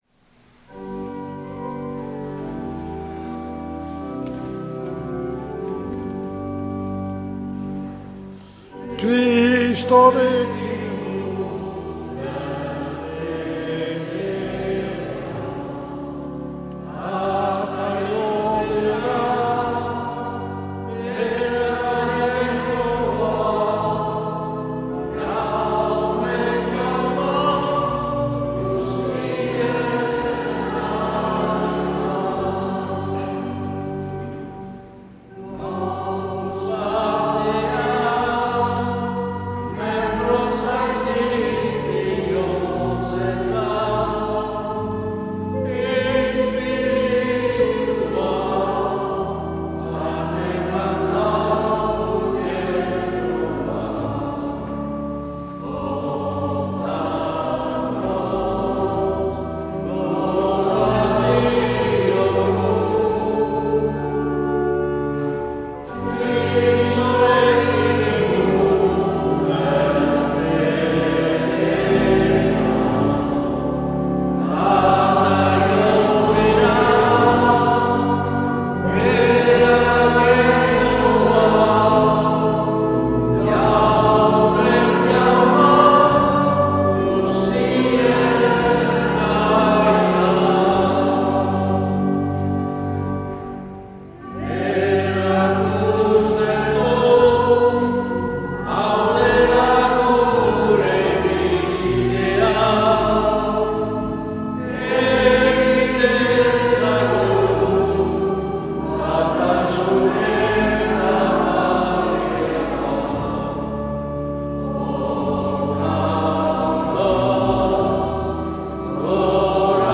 Igandetako Mezak Euskal irratietan